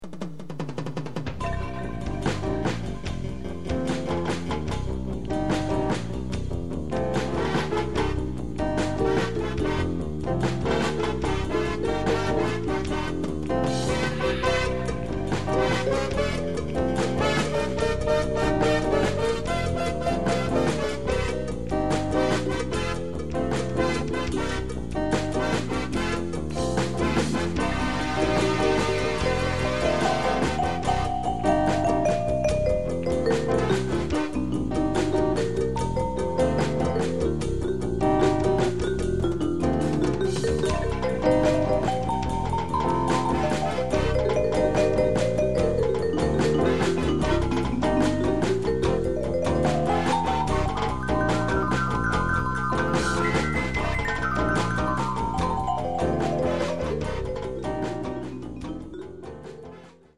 It's eclectic mix of musical styles